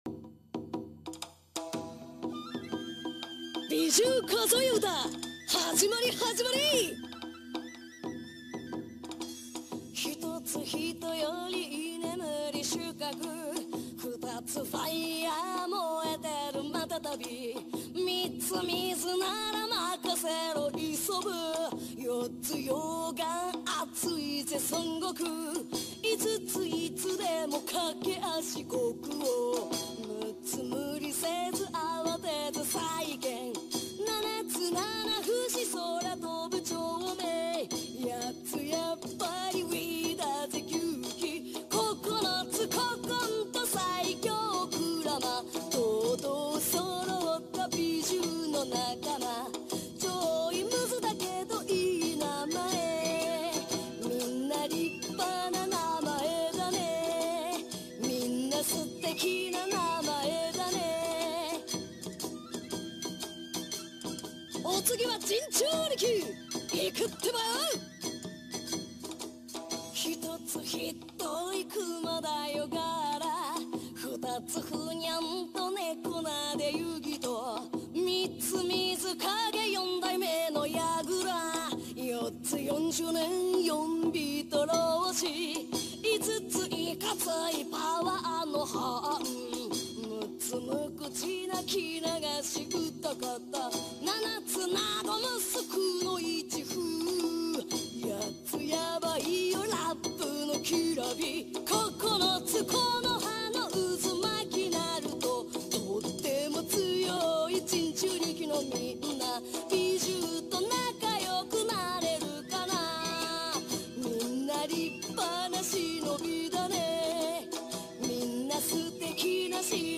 Super in love with this song coz its catchy!